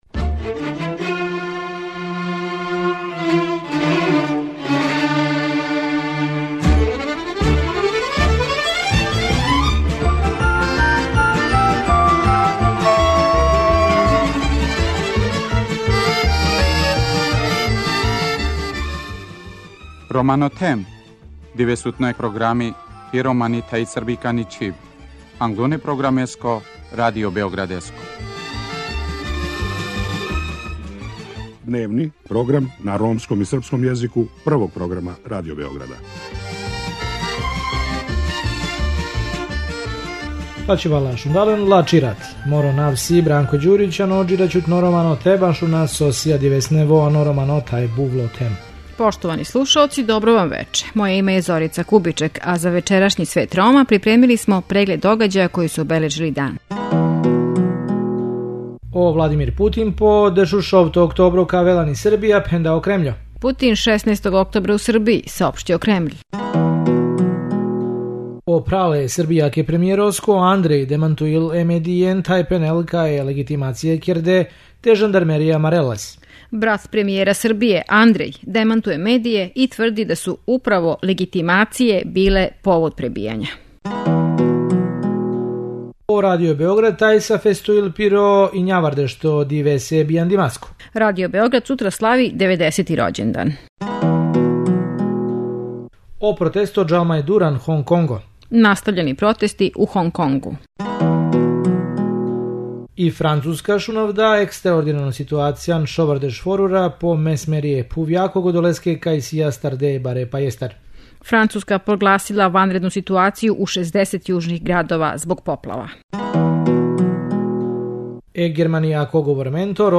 Чућемо и извештај из Брисела са студијског путовања које организује међународна организација TASCO за представнике ромских невладиних организација из седам држава Западног Балкана и Турске.